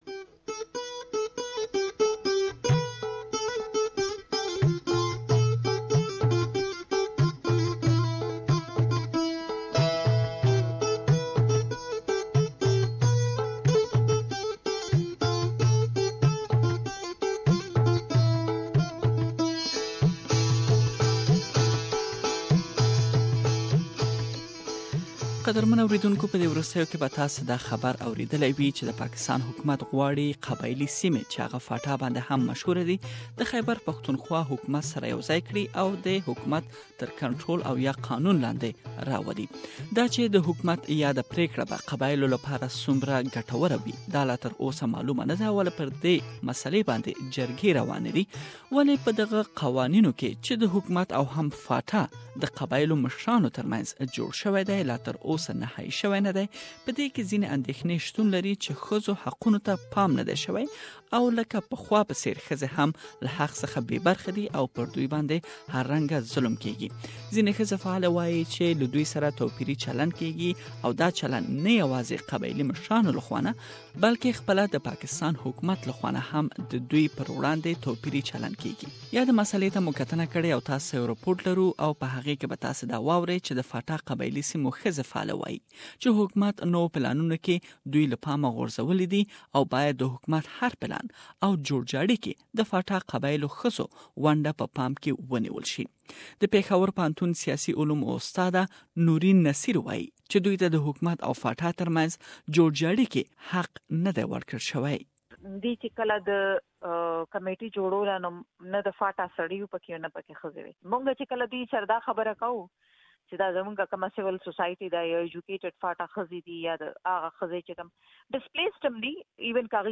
Tribal women say they have been ignored during the process and they claim that the Pakistan government allegedly have a discriminatory policy towards tribal women. We have gathered their concerns that you can listen to the full report here.